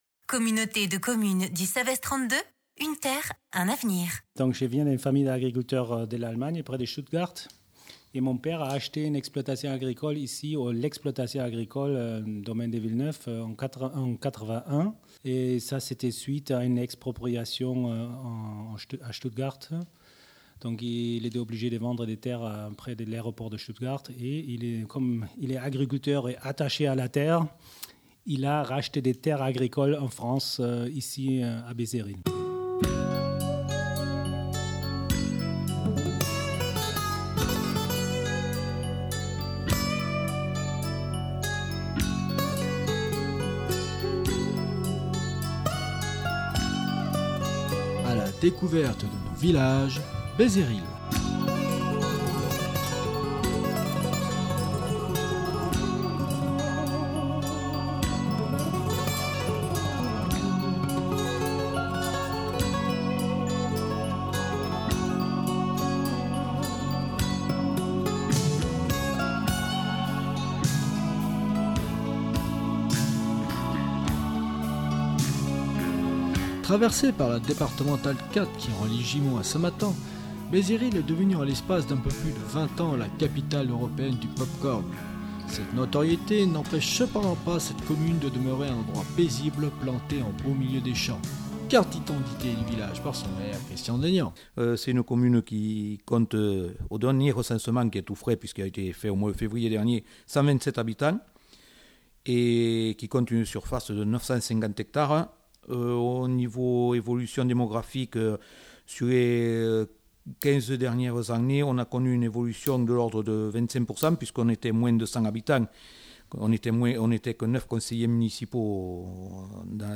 Reportage sonore